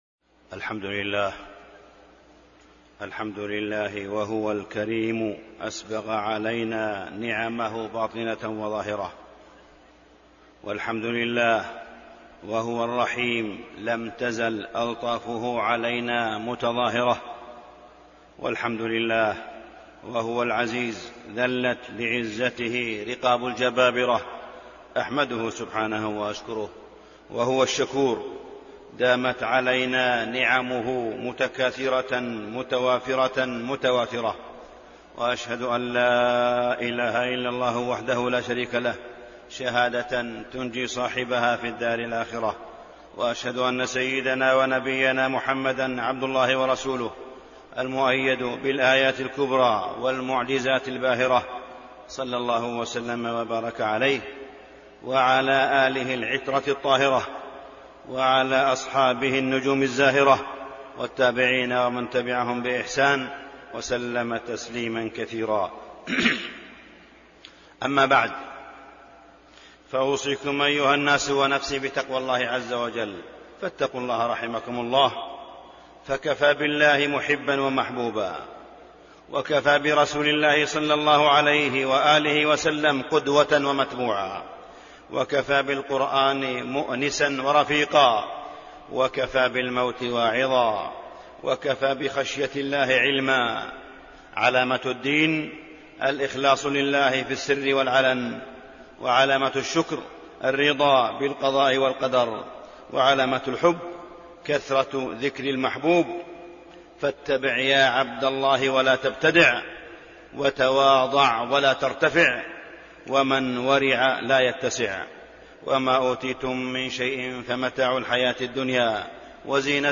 تاريخ النشر ١٩ رمضان ١٤٣٢ هـ المكان: المسجد الحرام الشيخ: معالي الشيخ أ.د. صالح بن عبدالله بن حميد معالي الشيخ أ.د. صالح بن عبدالله بن حميد التوبة والعشر الأواخر من رمضان